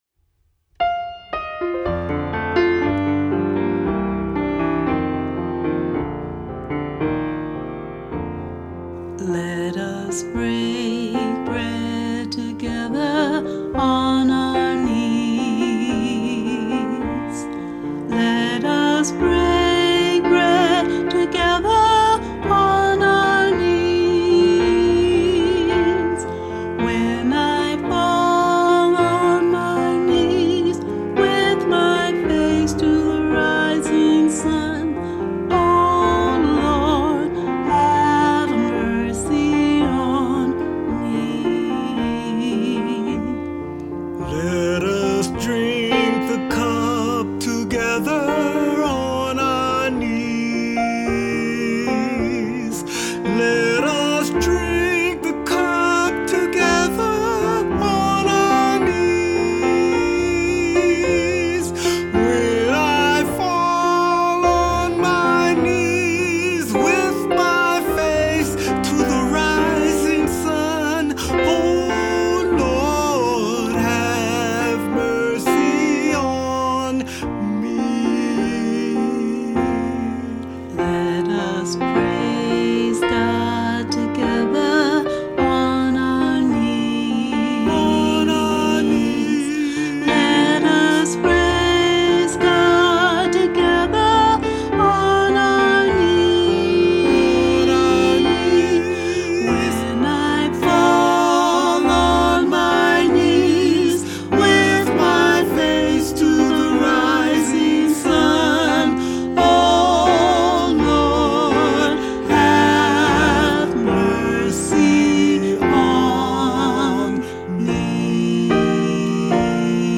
• It ends with four hymns for congregational singing, starting with “